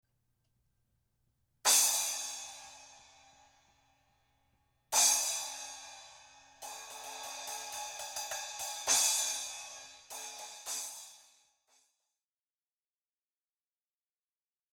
trx-icon-9-inch-splash.mp3